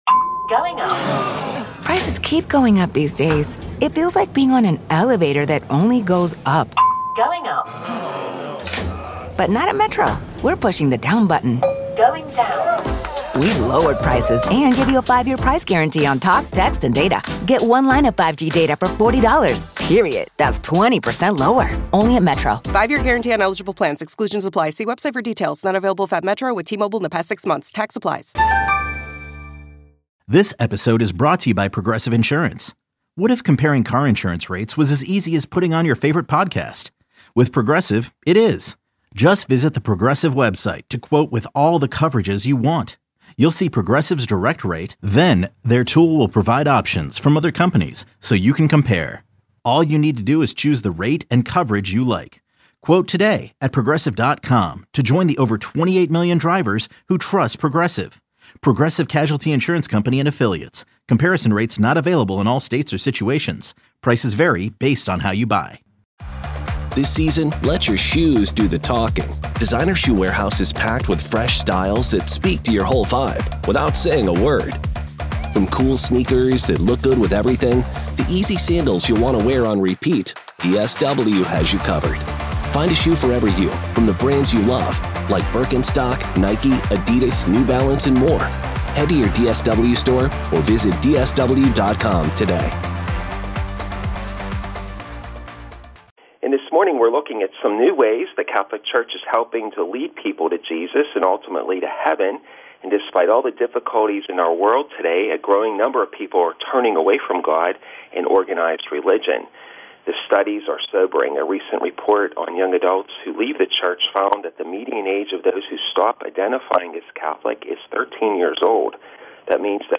Catholic Education Plus Catholic Education Plus 6-21-20 How the Catholic Church is helping to lead people to Jesus. An interview